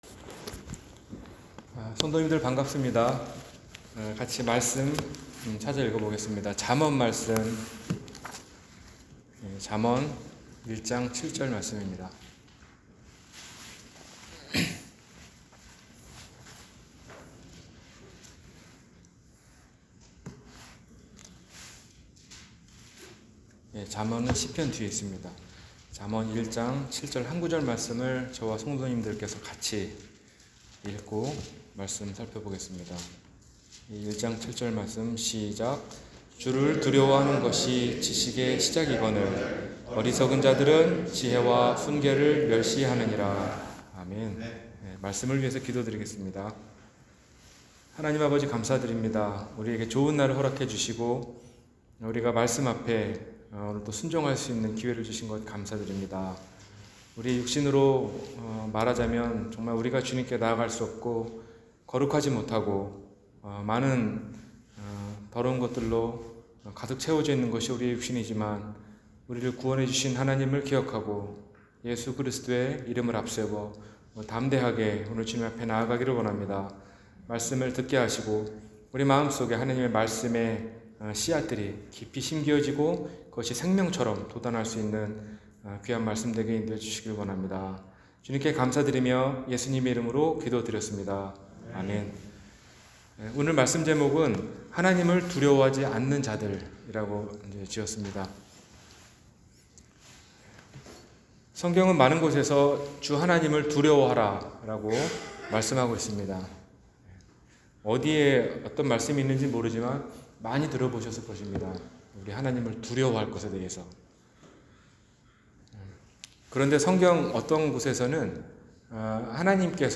하나님을 두려워하지 않는 자들 – 주일설교